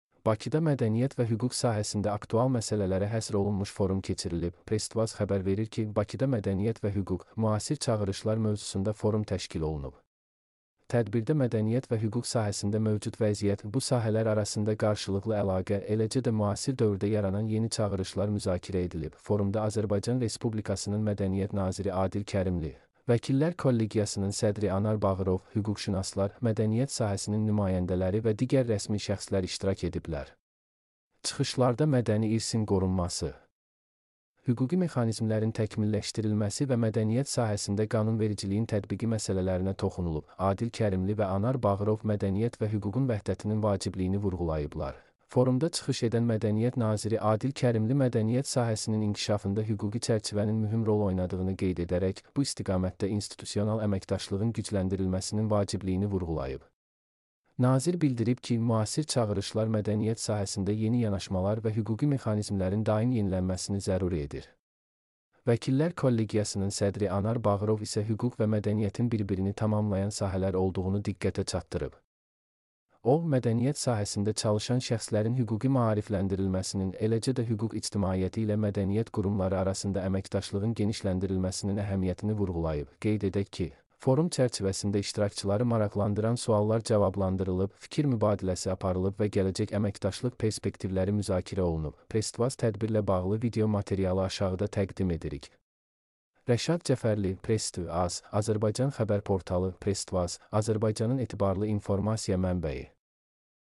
mp3-output-ttsfreedotcom-38.mp3